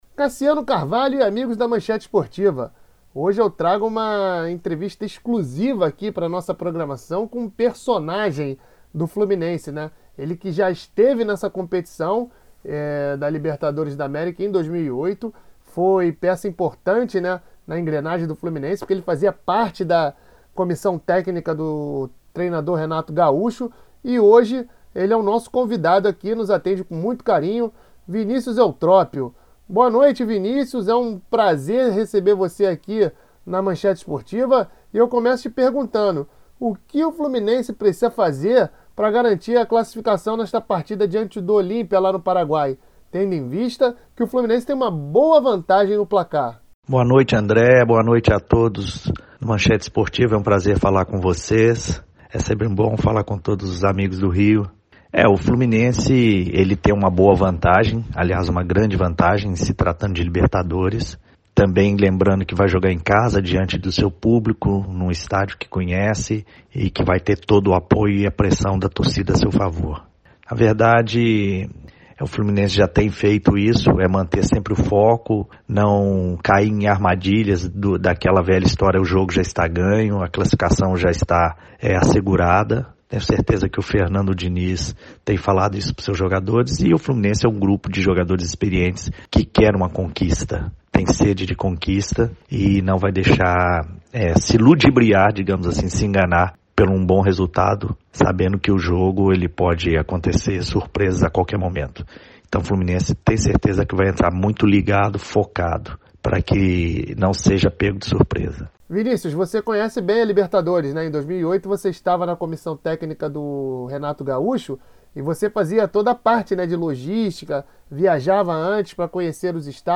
Hoje trazemos para uma entrevista exclusiva com um personagem do Fluminense. ele que já esteve na Libertadores em 2008. peça importante da comissão técnica de Renato Gaucho.